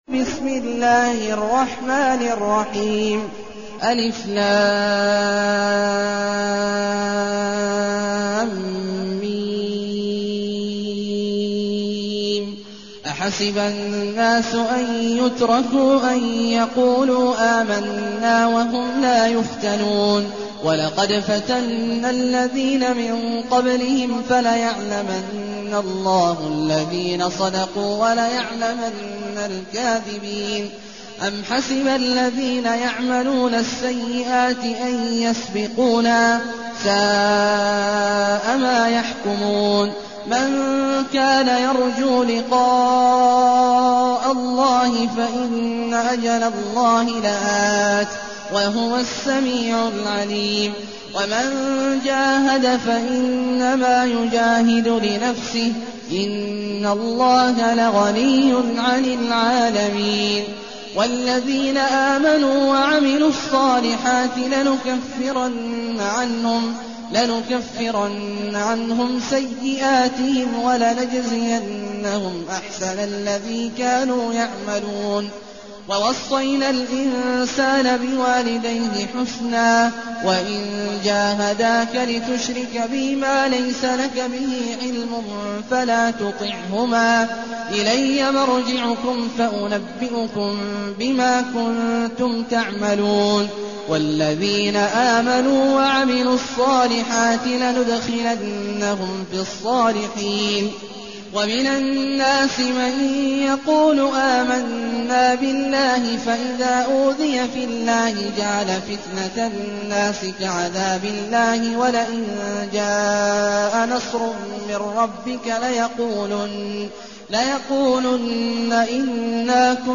المكان: المسجد النبوي الشيخ: فضيلة الشيخ عبدالله الجهني فضيلة الشيخ عبدالله الجهني العنكبوت